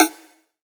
SNARE 01  -L.wav